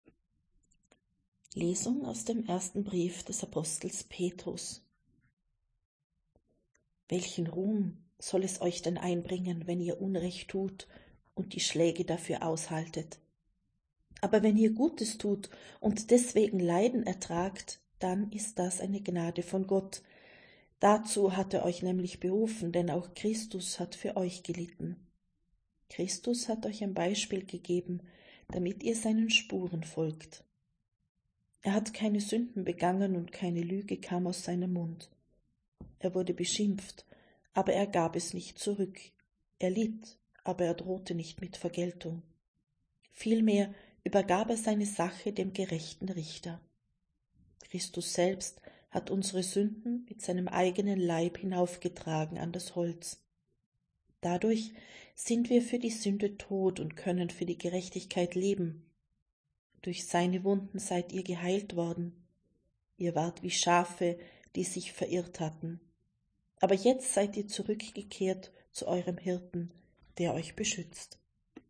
Wir wollen einen Versuch starten und werden ab dem Beginn des neuen Lesejahres die Texte in der Länge der biblischen Verfasser lesen.